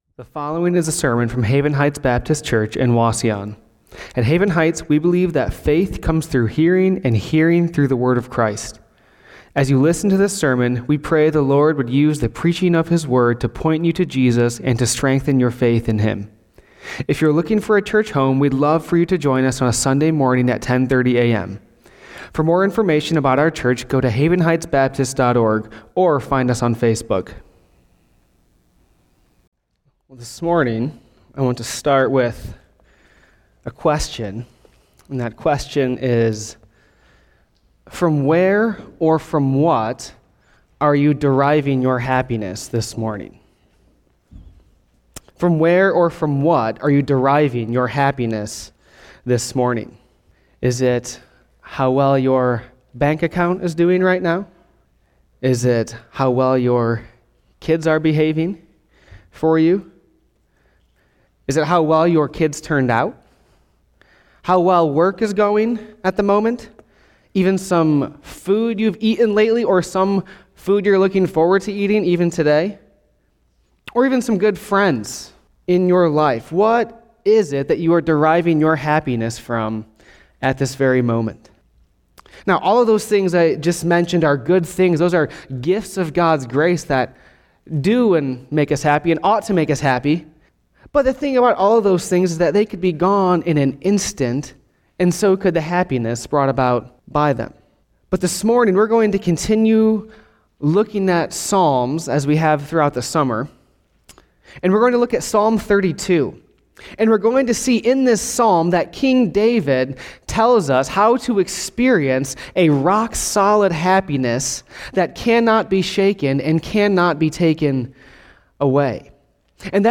Sermons | Haven Heights Baptist Church